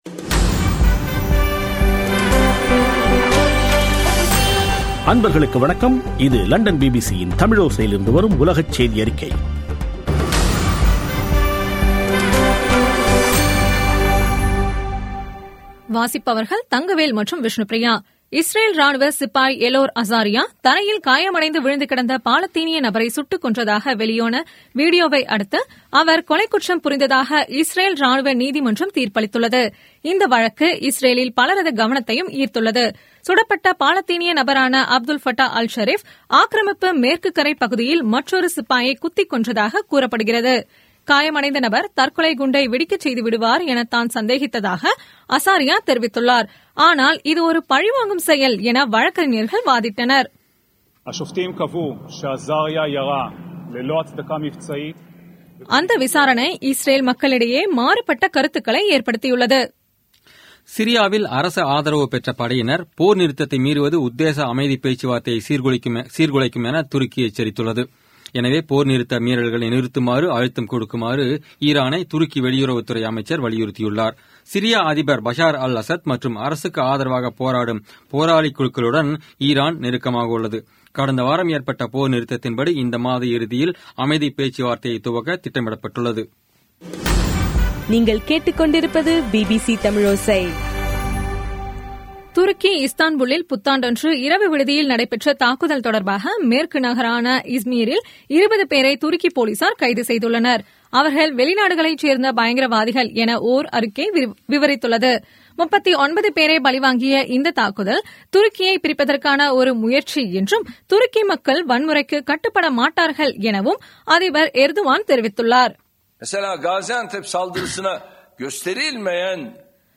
பிபிசி தமிழோசை செய்தியறிக்கை (04/01/2017)